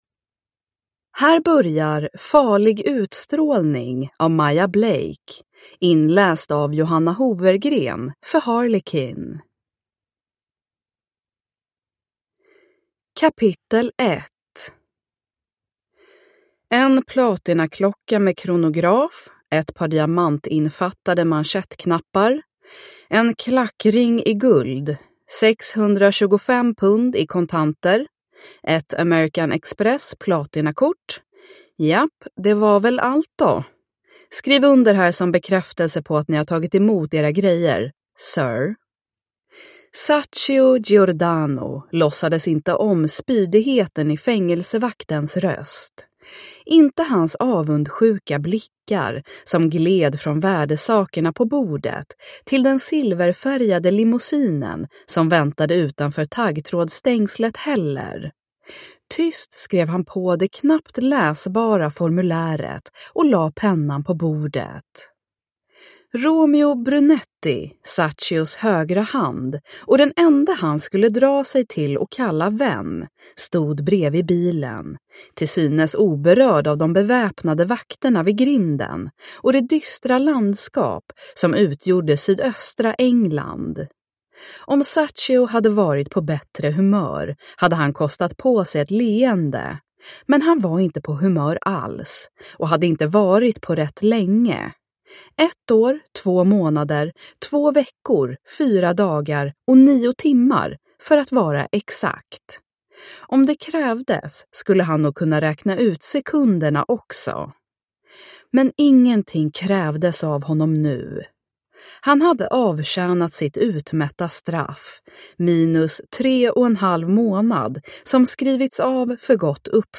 Farlig utstrålning (ljudbok) av Maya Blake